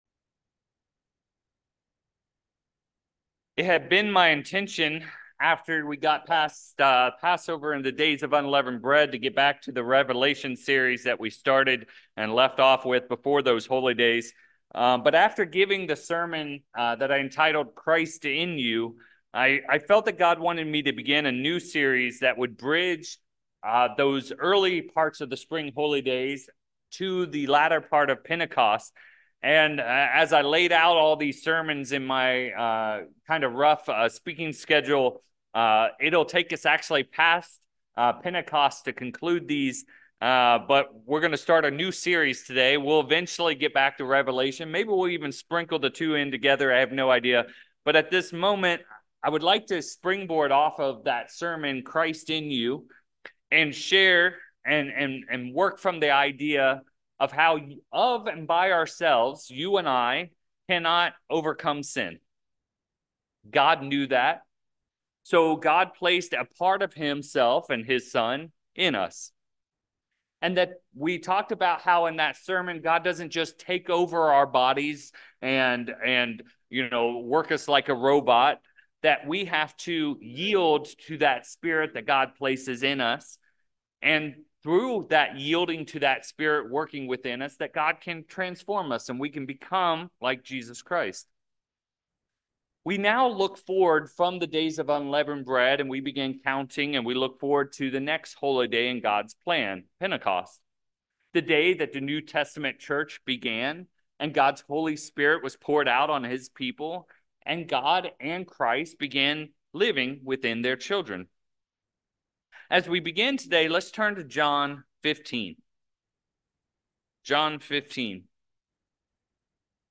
In this sermon series, we are going to move through nine qualities of the God Family known more commonly as the Fruits of the Spirit. In this first sermon, we will examine "kindness".